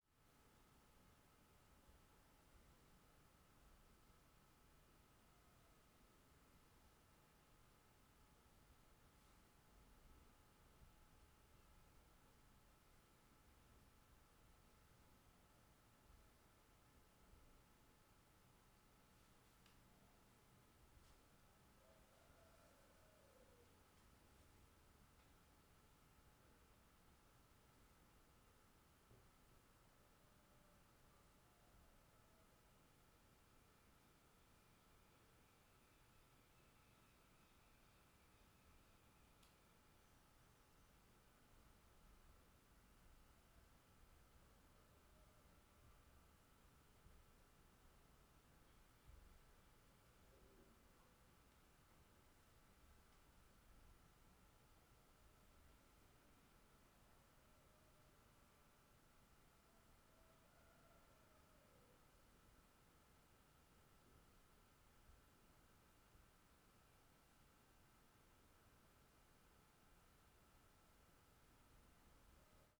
CSC-02-027-GV - Ambiencia de Quarto Pequeno em Casa no Paranoa.wav